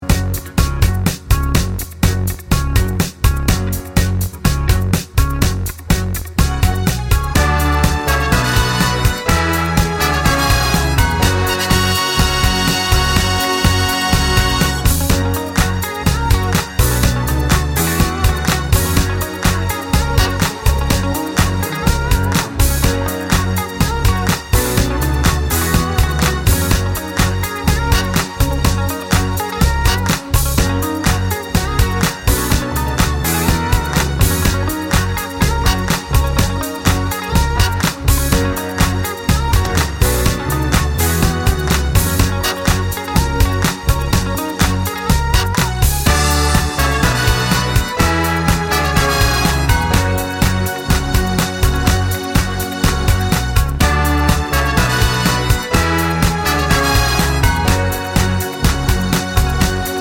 no Backing Vocals Disco 3:48 Buy £1.50